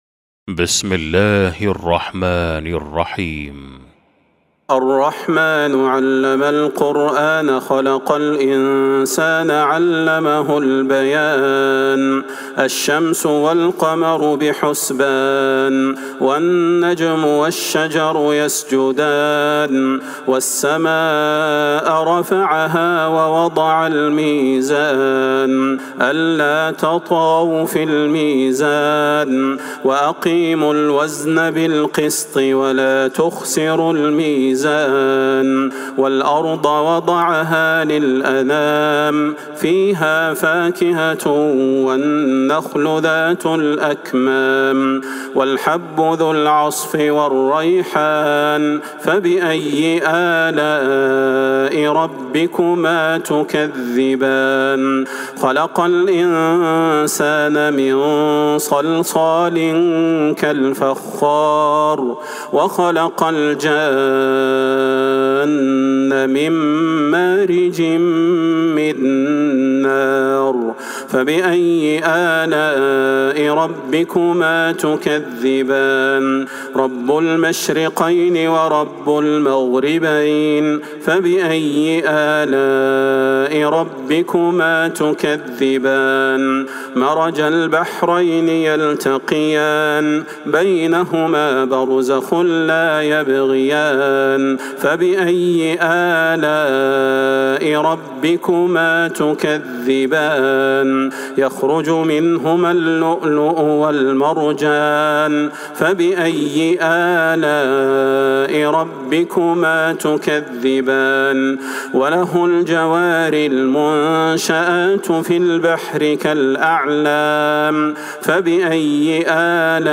سورة الرحمن Surat Ar-Rahman > مصحف تراويح الحرم النبوي عام ١٤٤٣ > المصحف - تلاوات الحرمين